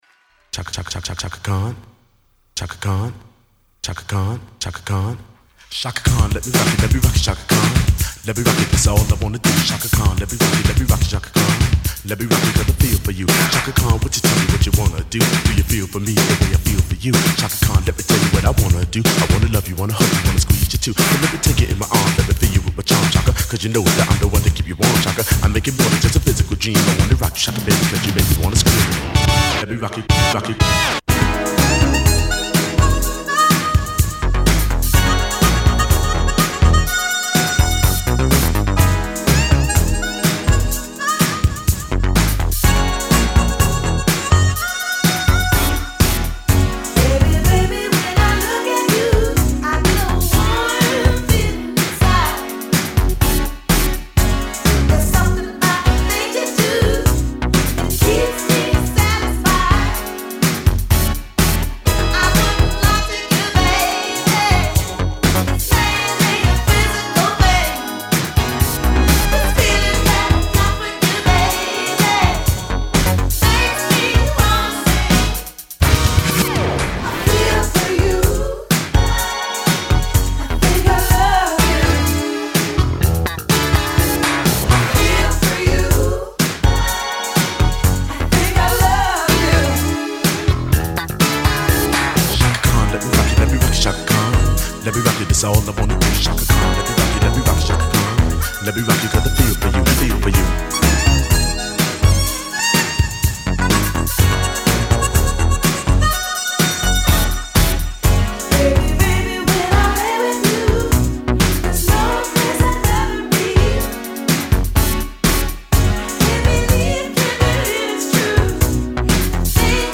harmonica